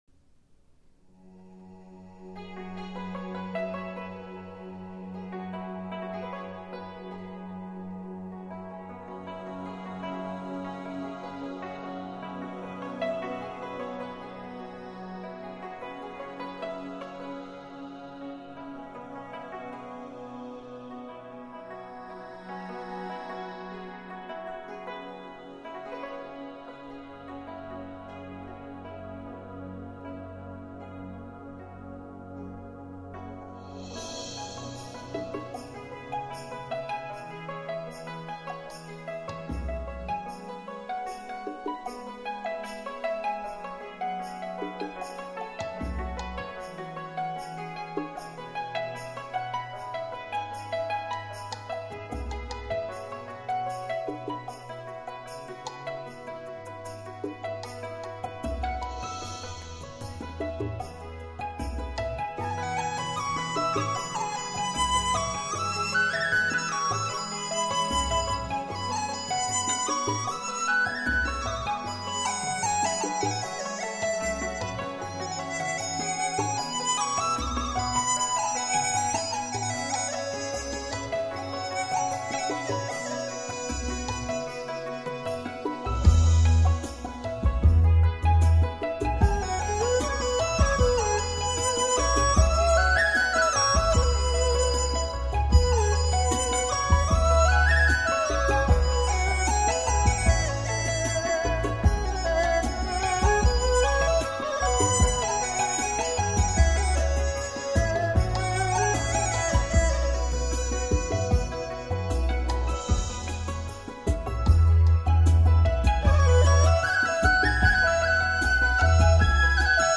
【民乐新编】
这张专辑由三位中国民乐音乐家和美国的合成器音乐家合作
中国传统乐器与现代乐器的完美组合，曲目都是我们熟悉
扬琴
竹笛
二胡
合成器
只要两个立体声喇叭，就能产生自然环绕效果。
极低频丰沛，敬请留意音量。